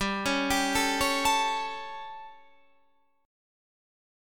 G Suspended 2nd Flat 5th